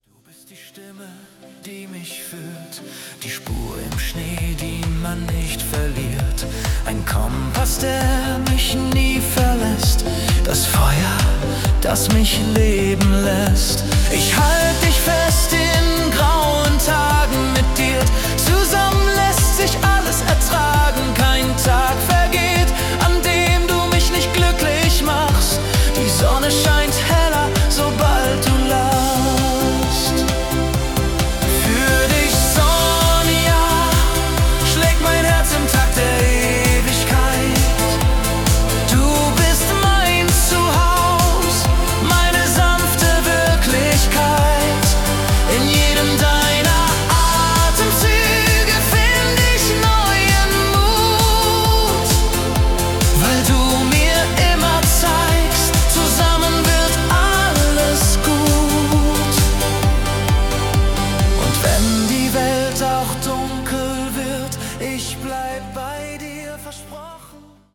Schlager